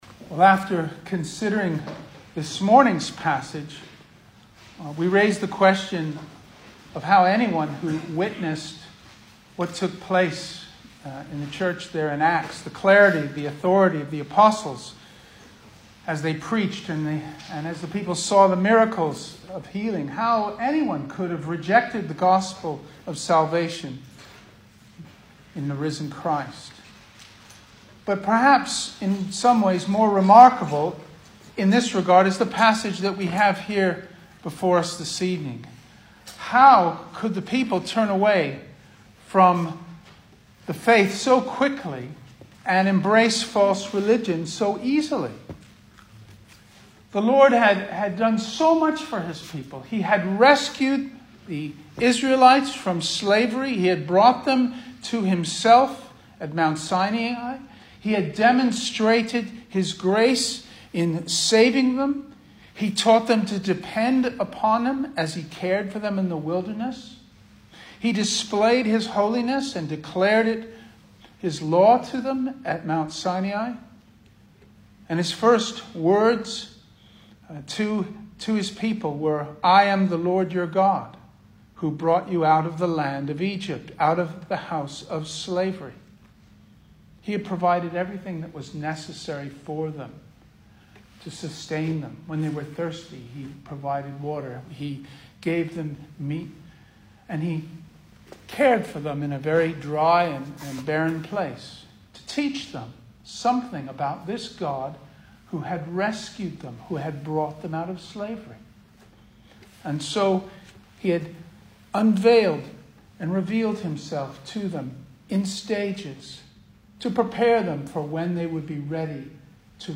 Service Type: Sunday Evening
Single Sermons